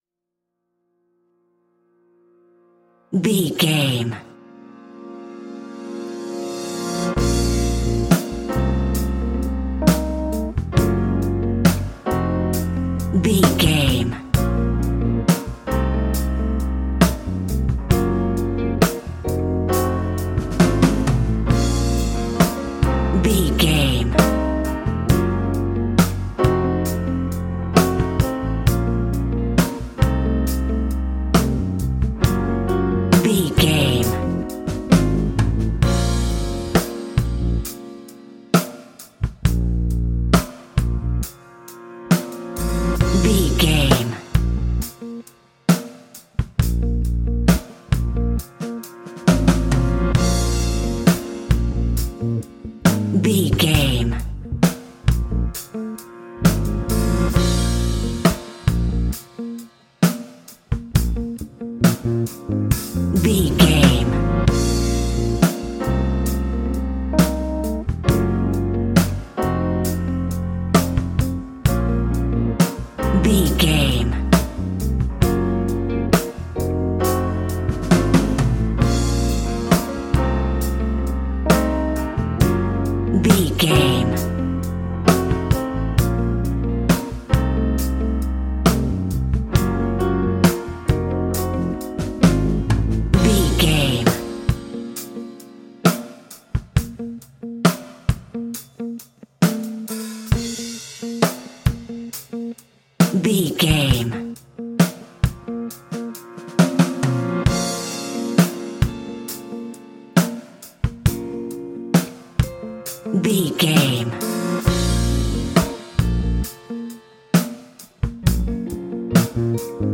Epic / Action
Fast paced
In-crescendo
Uplifting
Ionian/Major
A♯
hip hop